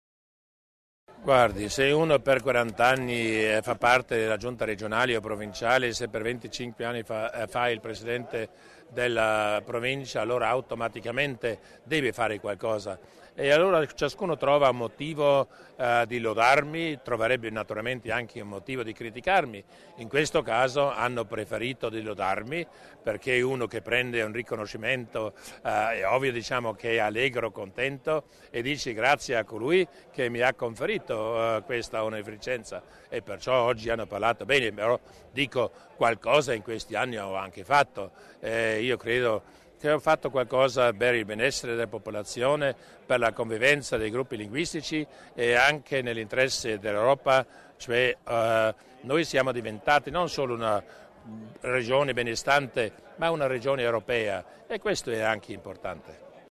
Il Presidente Durnwalder illustra le motivazioni del conferimento del Grande Ordine di Merito